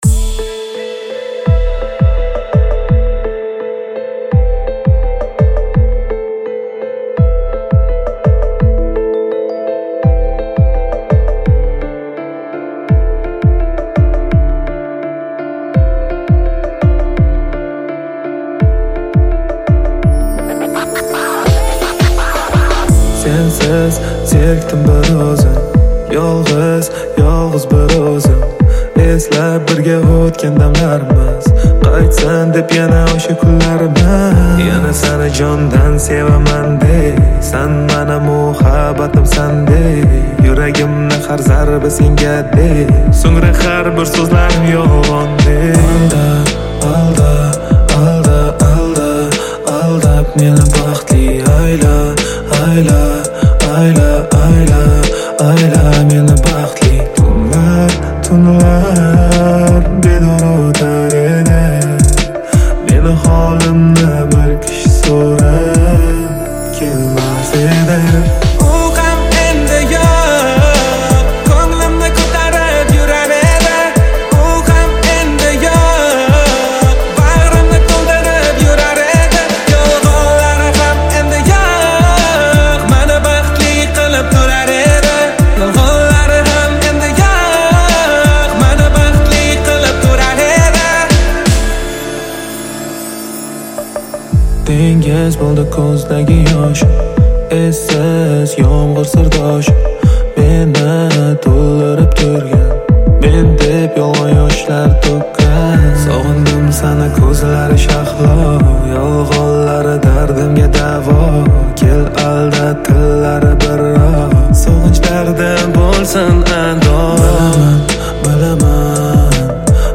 • Жанр: Узбекская музыка